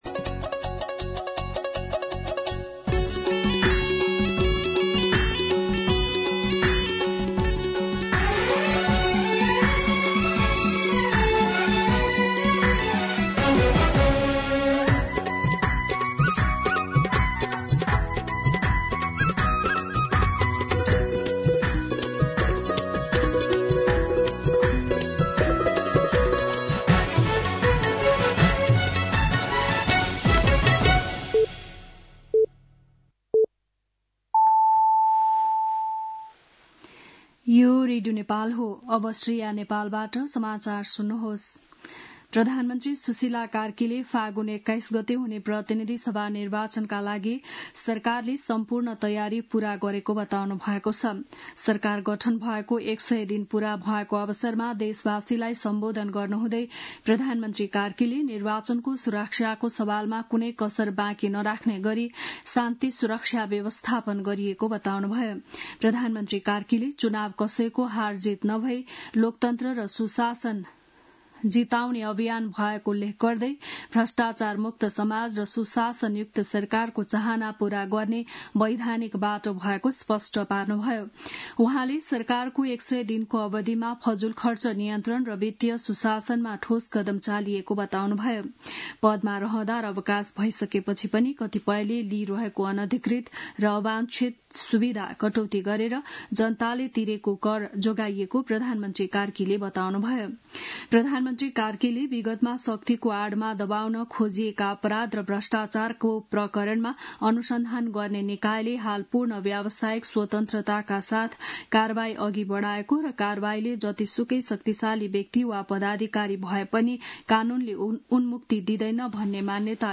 बिहान ११ बजेको नेपाली समाचार : ५ पुष , २०८२